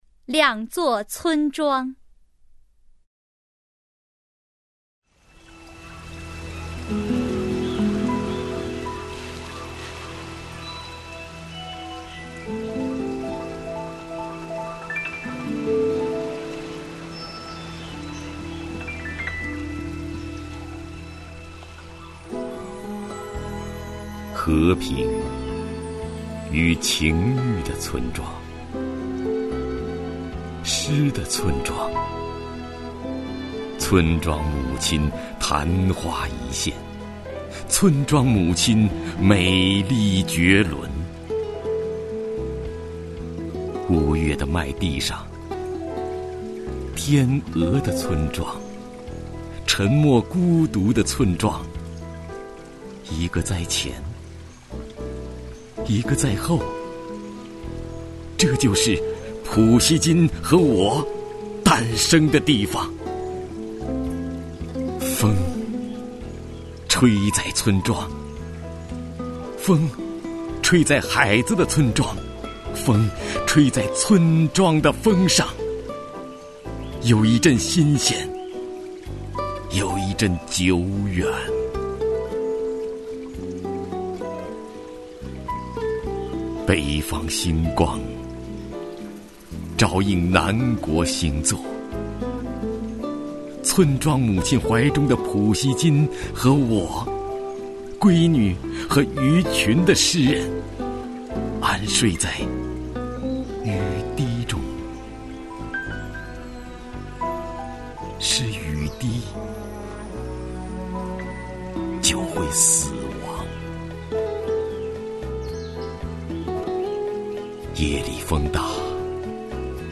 首页 视听 名家朗诵欣赏 徐涛
徐涛朗诵：《两座村庄》(海子)